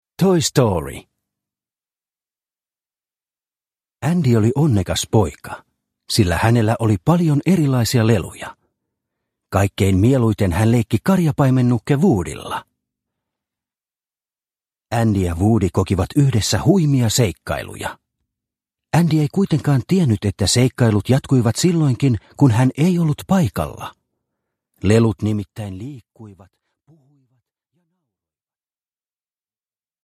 Toy Story – Ljudbok – Laddas ner